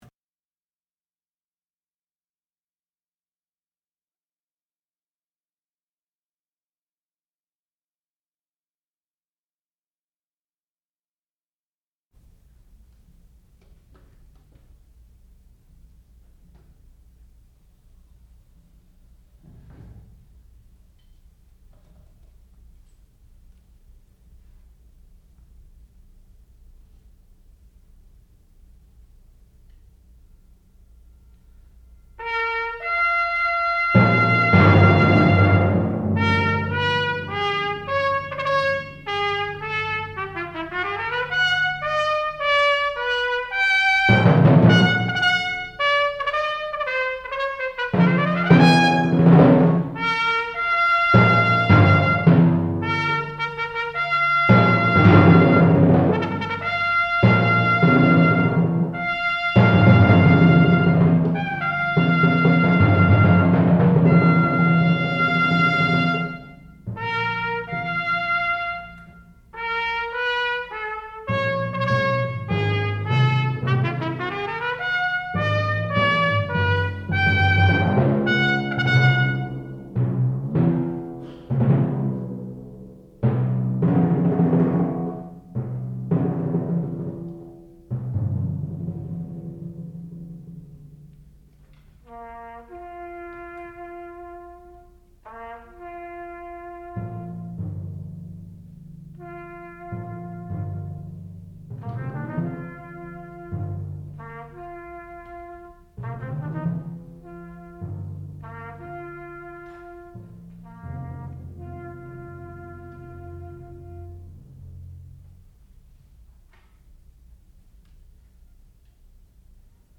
sound recording-musical
classical music
Master Recital
percussion